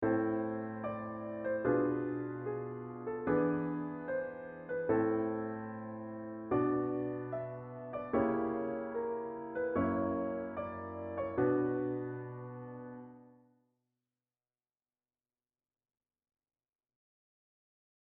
Here I’ve chosen a i-iio7-V7-i progression in the key of A minor.
Here I’ve chosen to modulate up a major second.
Notice that I don’t maintain the same chord voicing in both keys.
I also moved the F♯ bass note down an octave (V of Bm) for slight variation.